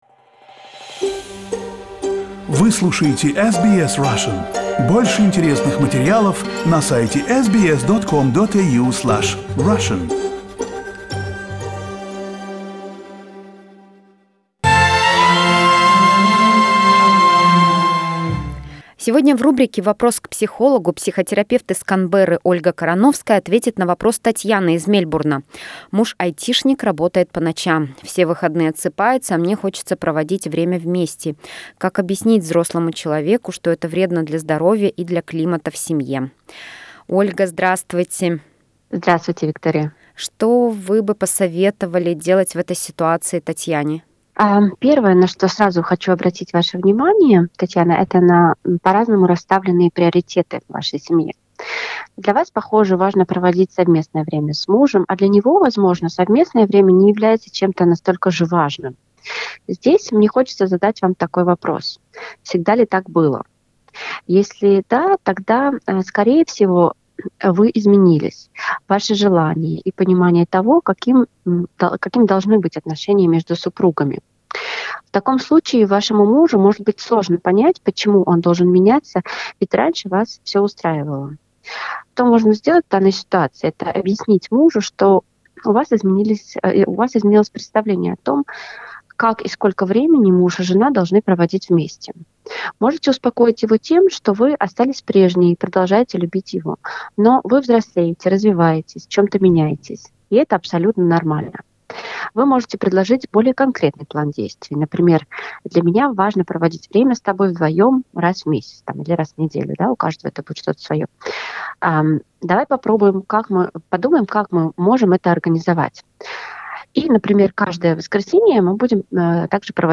A psychotherapist